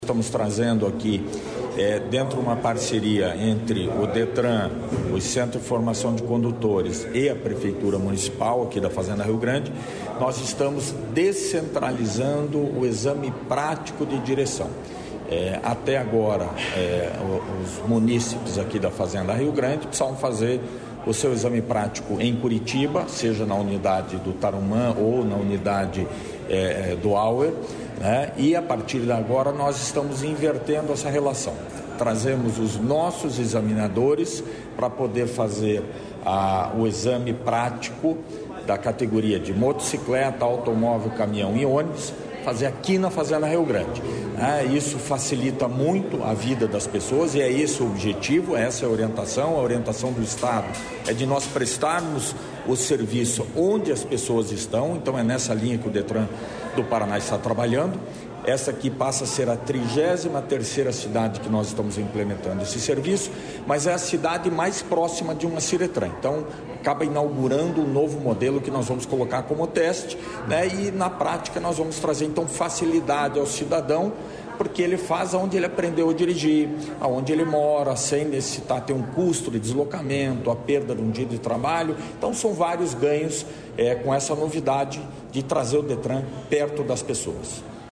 Sonora do diretor-presidente do Detran-PR, Adriano Furtado, sobre a parceria entre o Detran-PR e Fazenda Rio Grande